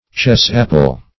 Chess-apple \Chess"-ap`ple\, n.
chess-apple.mp3